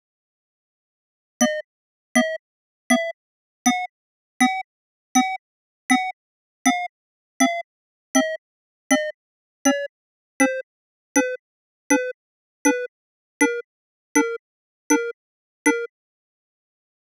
Data Sonification of Violent Crime Rate in USA 1986-2005